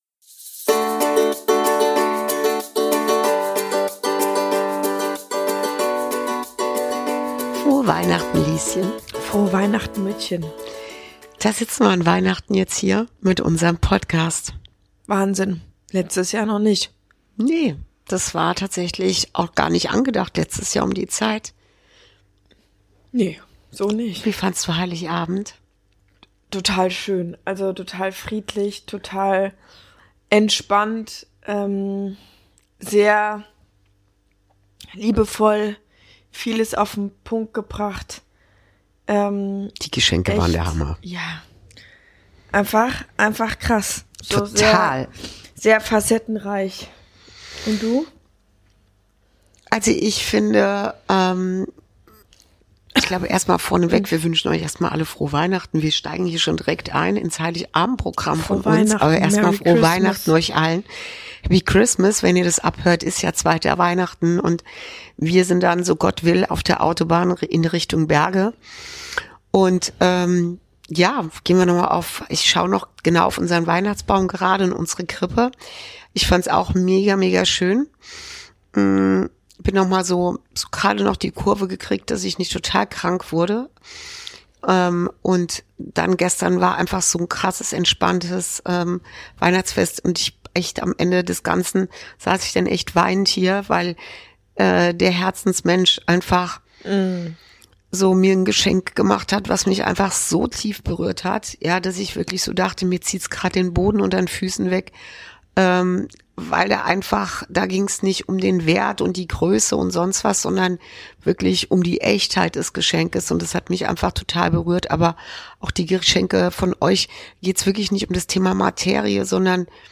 Folge 45: Weihnachten in der Transformation ~ Inside Out - Ein Gespräch zwischen Mutter und Tochter Podcast